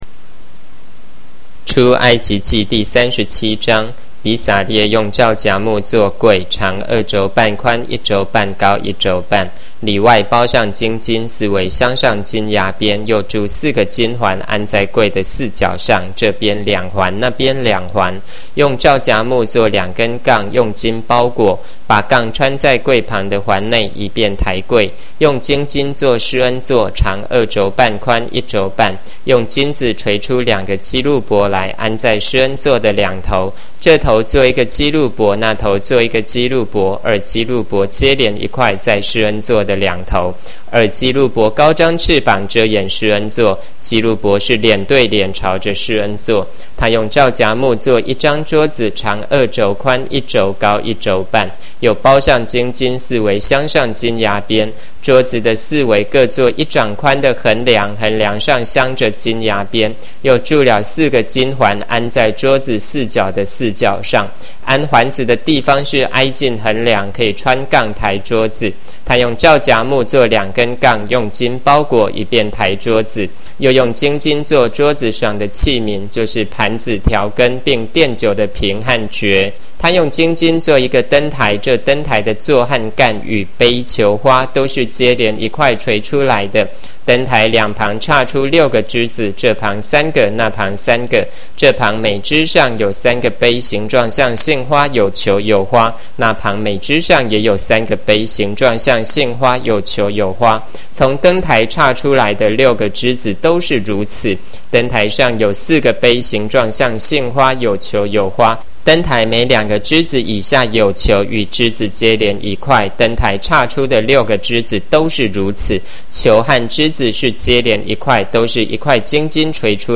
Spring 版和合本有聲聖經